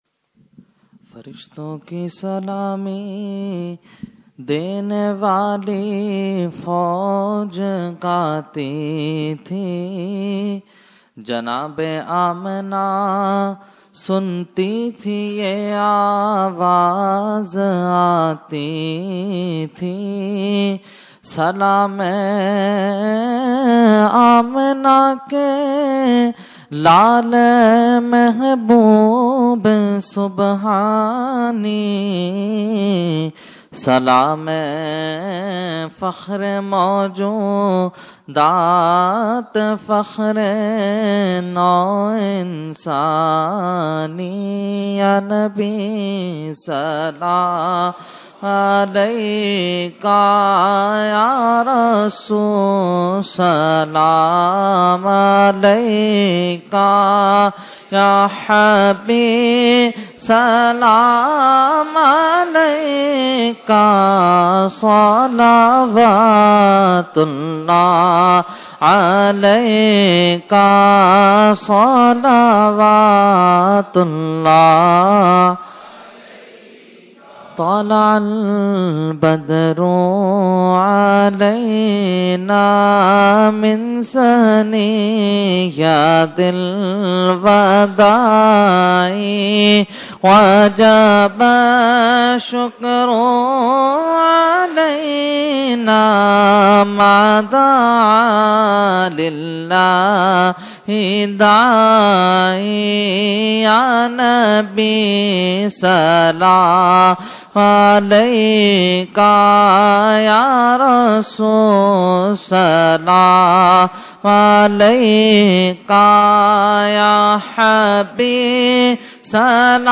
Category : Salam | Language : UrduEvent : Jashne Subah Baharan 2017